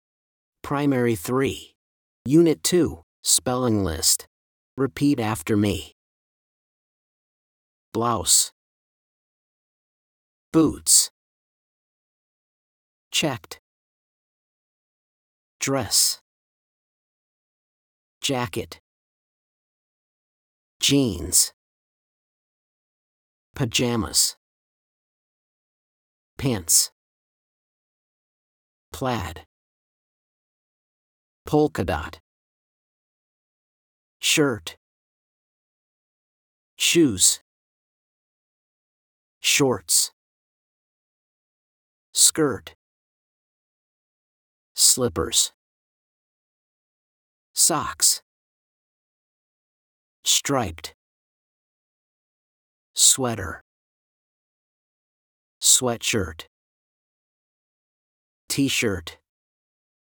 blankThese are the words on the spelling list. Listen and repeat after the teacher: